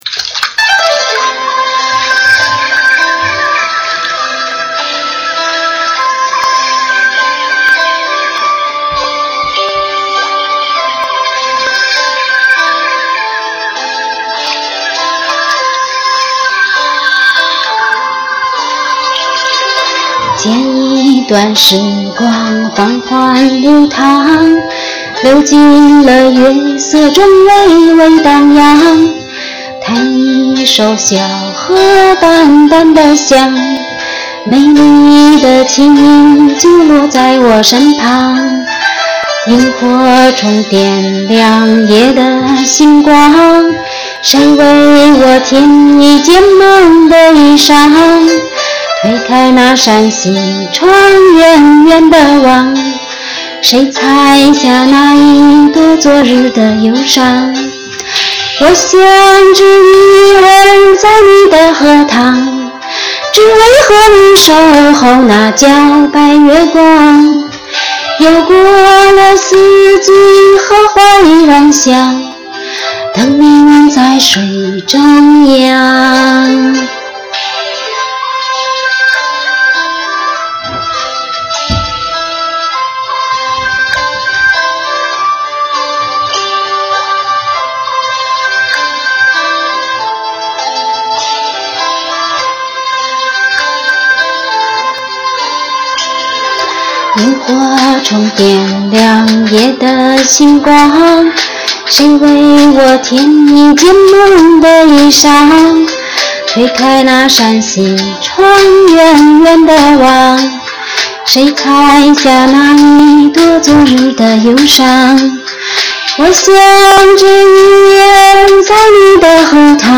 这回我可是用的乐队伴奏，没有敲破锅。只是录音时连个话筒都没有，对这电脑瞎喊，凑合着听吧。